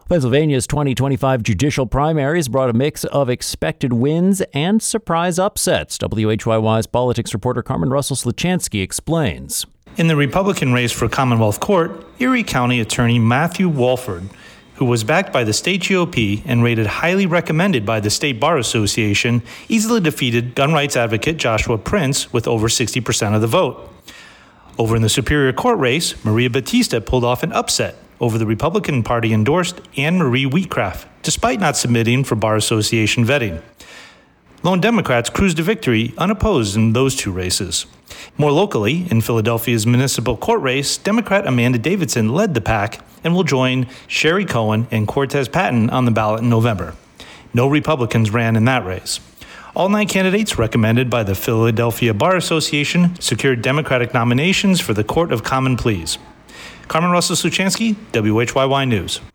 We're joined by Suzanne Simard, author of Finding the Mother Tree: Discovering the Wisdom of the Forest.